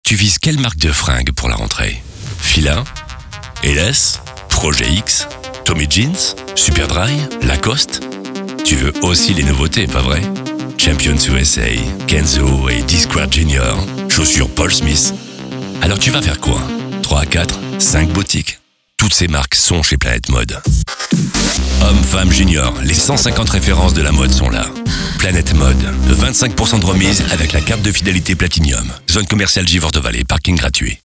Commercial
His deep and reassuring voice makes him the official voice of several radio stations.
He records his voice daily on a NEUMANN TLM 49 microphone, a large diaphragm microphone with a renowned warm sound.
SPOT RADIO -  GMS Enseigne Planet Mode.mp3